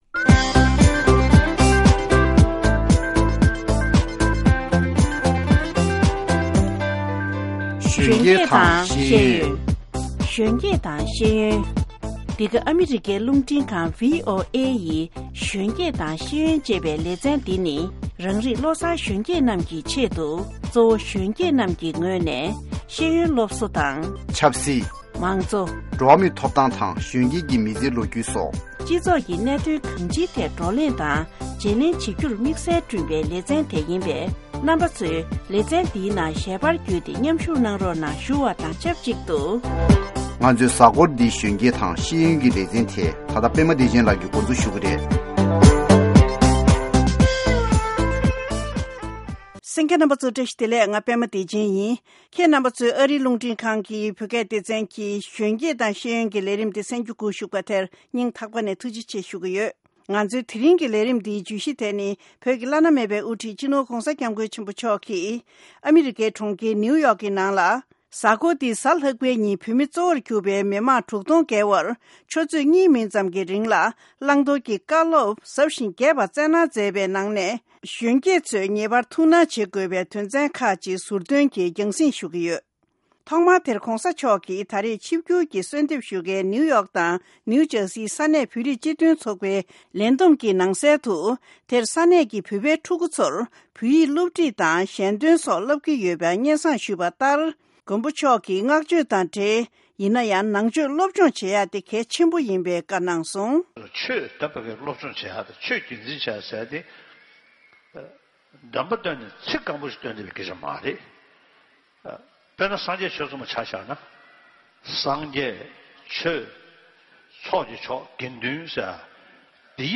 Excerpts of the Dalai Lama’s address to over six thousand people, predominantly Tibetans at the Convention Center, Manhattan, NYC on November 5, 2014. In almost two hours of talk to Tibetans, the Dalai Lama urged Tibetan children to study their rich language, so that they appreciate the depth of Tibetan culture. He also urged them to maintain their age old tradition of love and compassion.